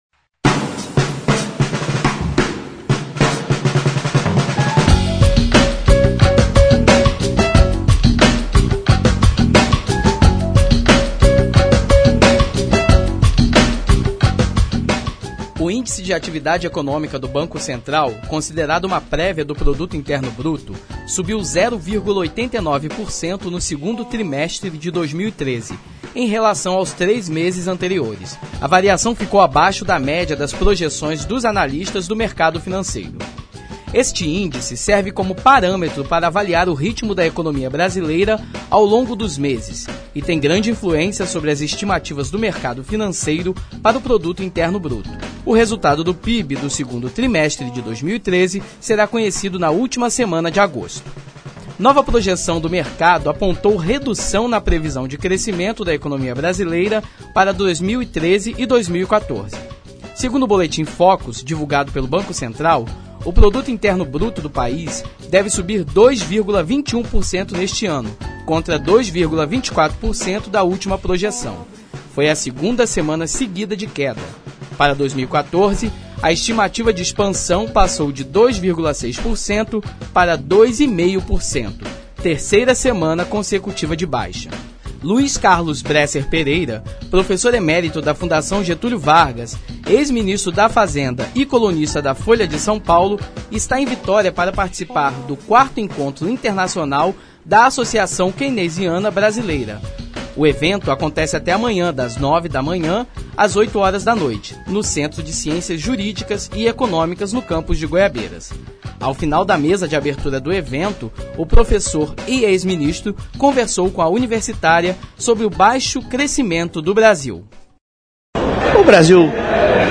Entrevista Bresser Pereira